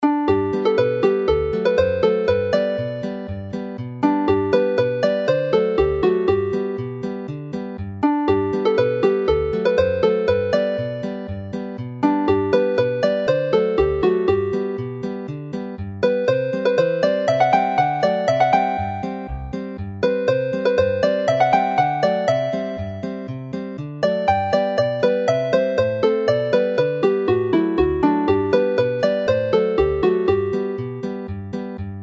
Rich (yellow) Cream - 32 bar adaptation
The lyrical Mwynen Merthyr is clearly from the South; Hufen Melyn is a familiar and catchy melody which does not fit into normal dance tune patterns, whilst Gwreiddyn y Pren Ffawydd is a standard 16-bar 2A 2B.